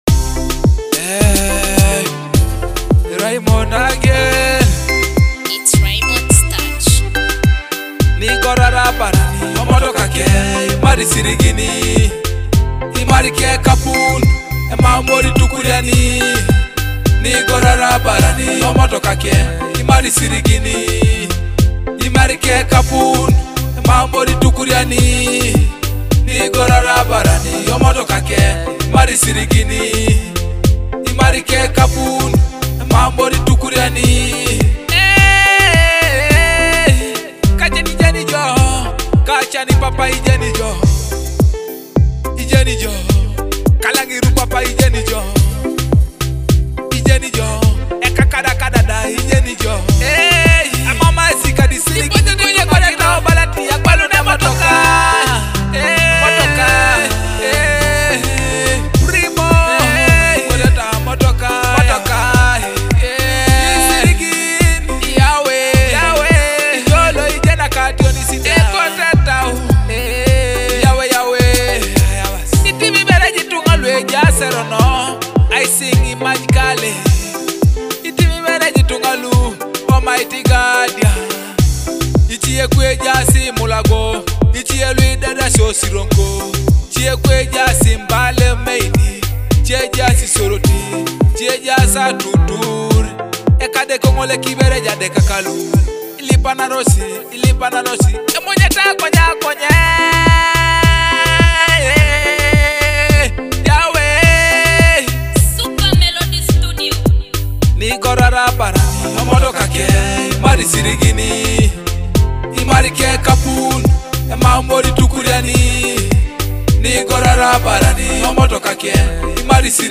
With soulful vocals and deep lyrical meaning